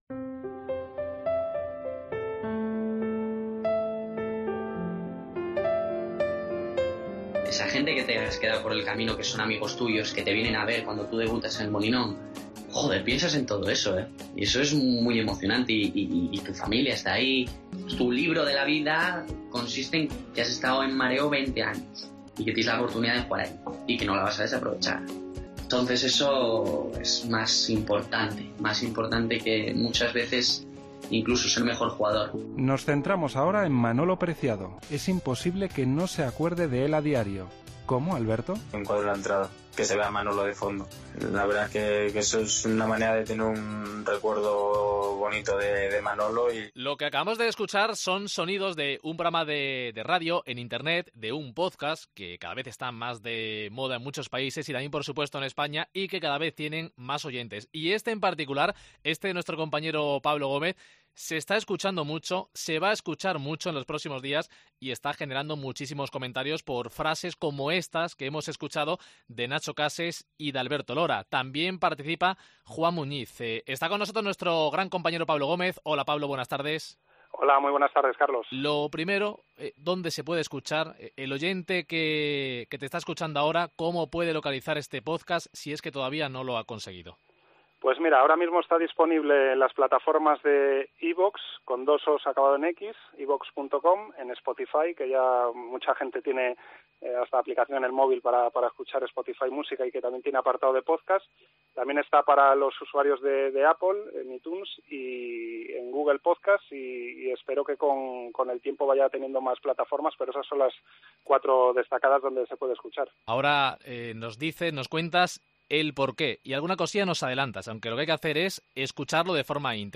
Programa especial